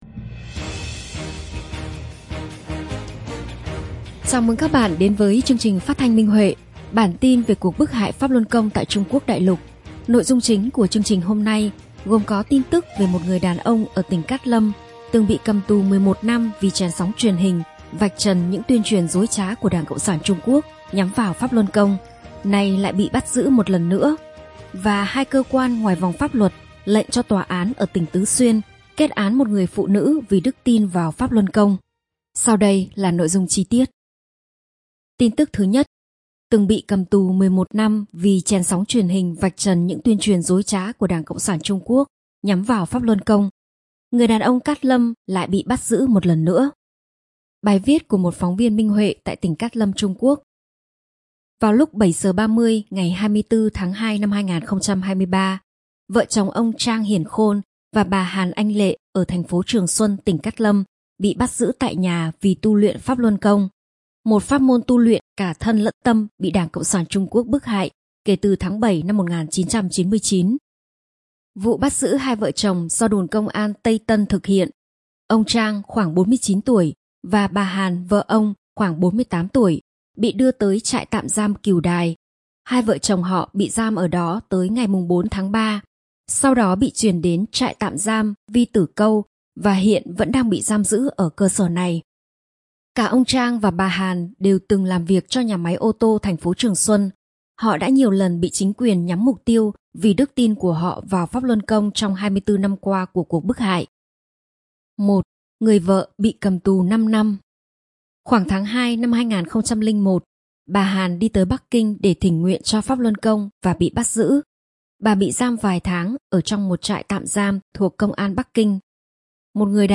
Chương trình phát thanh số 22: Tin tức Pháp Luân Đại Pháp tại Đại Lục – Ngày 12/7/2023